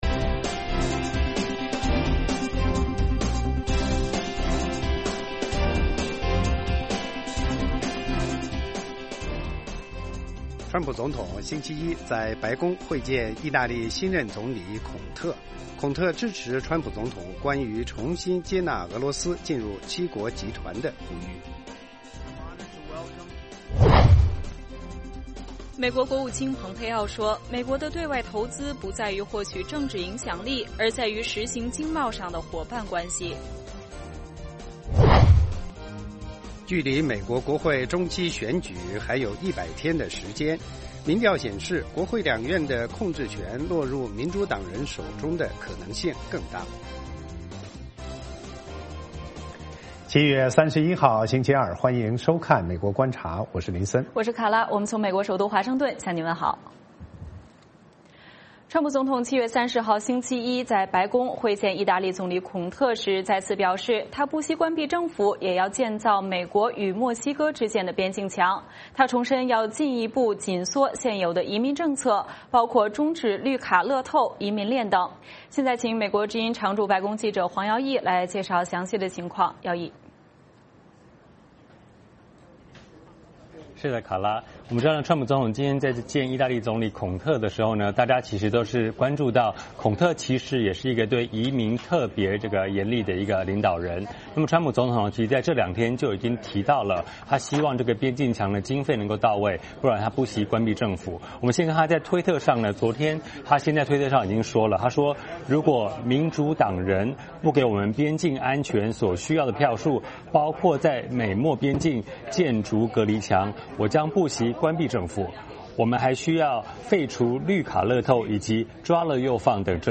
美国之音中文广播于北京时间晚上8－9点重播《VOA卫视》节目(电视、广播同步播出)。
“VOA卫视 美国观察”掌握美国最重要的消息，深入解读美国选举，政治，经济，外交，人文，美中关系等全方位话题。节目邀请重量级嘉宾参与讨论。